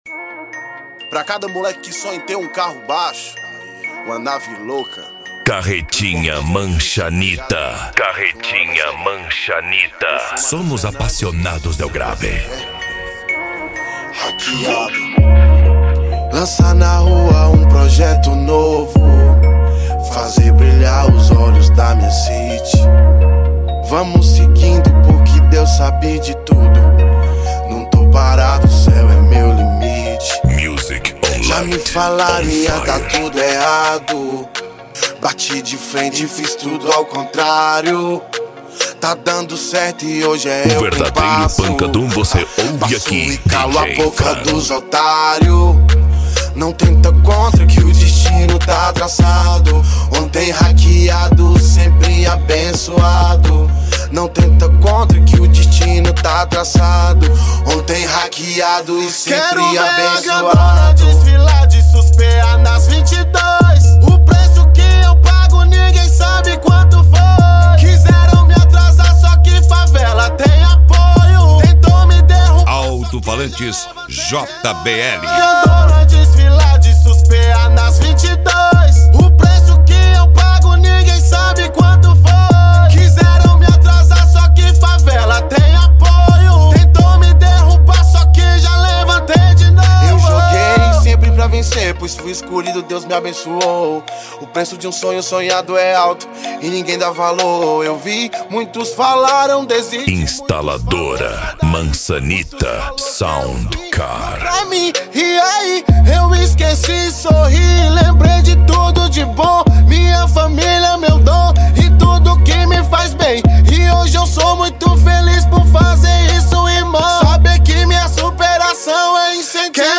Bass
Racha De Som